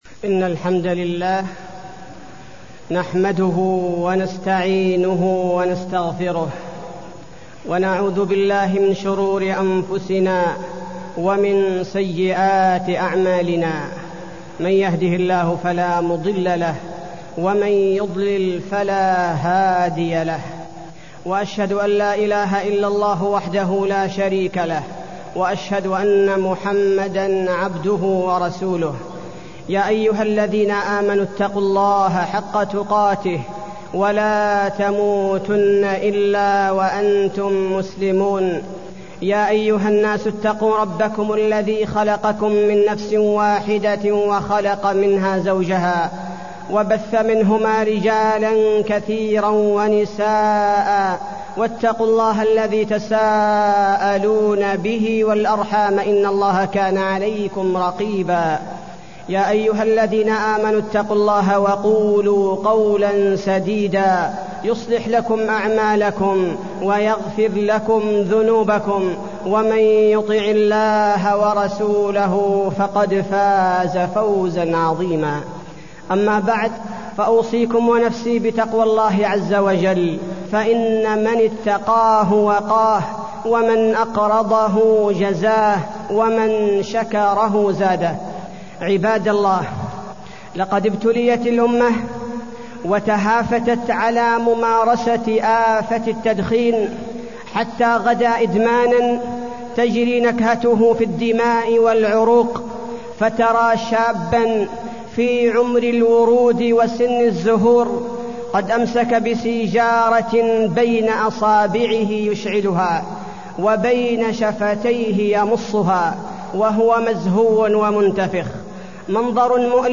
تاريخ النشر ١٥ صفر ١٤٢١ هـ المكان: المسجد النبوي الشيخ: فضيلة الشيخ عبدالباري الثبيتي فضيلة الشيخ عبدالباري الثبيتي التدخين